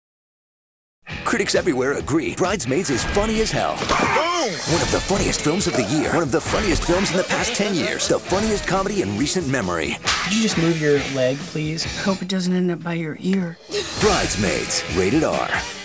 Brides Maids TV Spots